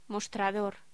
Locución: Mostrador
voz